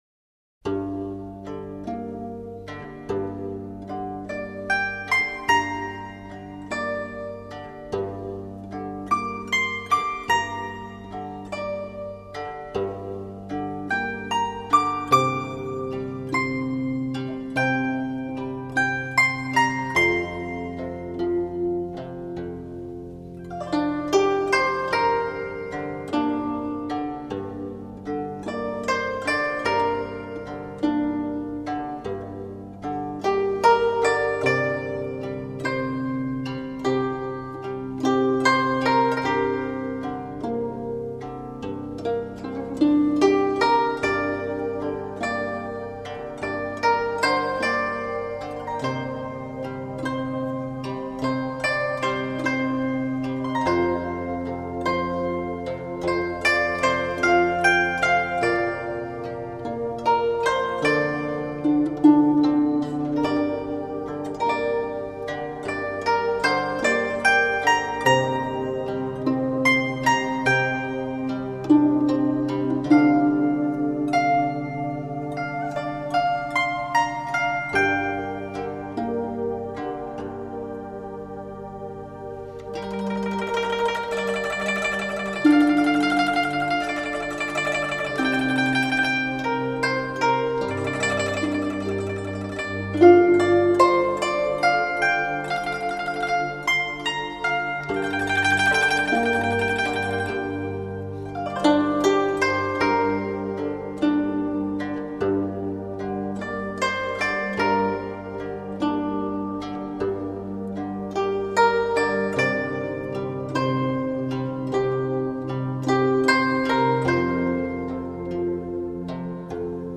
音色轻柔、典雅而高贵
[古筝]
轻柔、典雅、还有高贵。